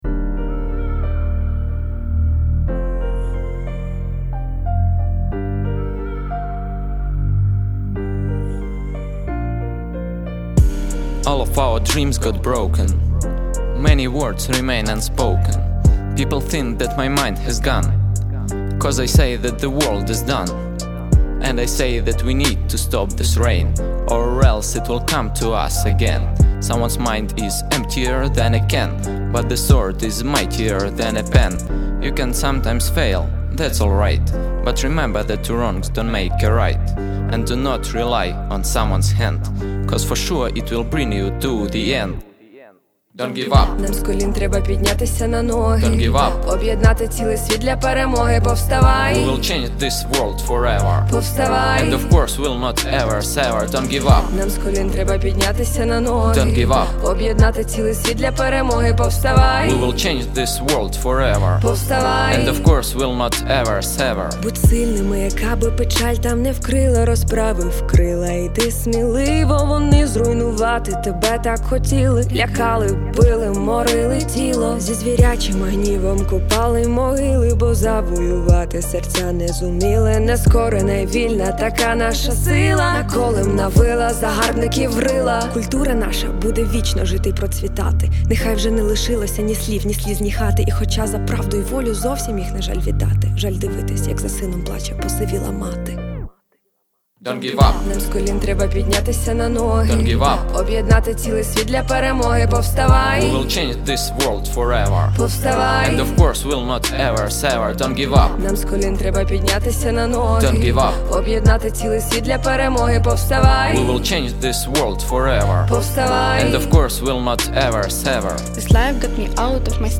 In nur jeweils drei Tagen haben 6 junge Menschen im Rahmen des Projektes „In Action“- startklar in die Zukunft einen RAP-Song entwickelt, d.h. einen gemeinsamen Refrain und ihre eigene Strophe geschrieben, das Ganze zu einem ausgewählten Beat gerappt bzw. gesungen und im Studio des Musikzentrums unter professionellen Bedingungen aufgenommen.